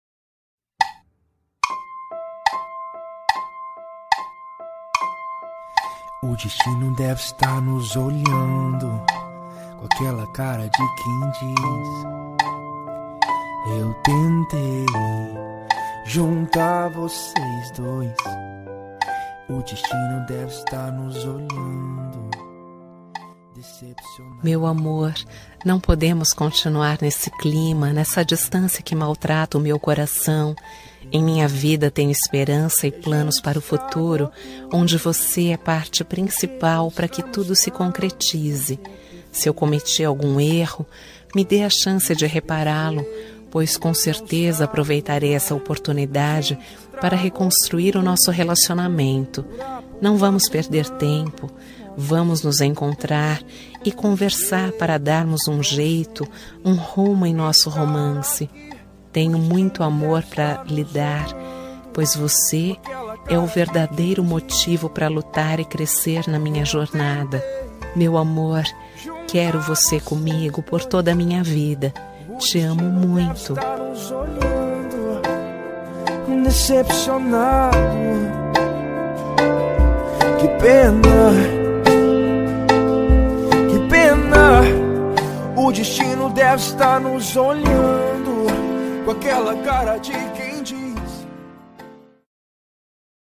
Reconciliação – Voz Feminina – Cód: 03530